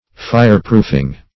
Search Result for " fireproofing" : The Collaborative International Dictionary of English v.0.48: Fireproofing \Fire"proof`ing\, n. The act or process of rendering anything incombustible; also, the materials used in the process.